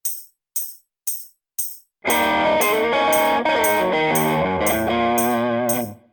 Méthode pour Guitare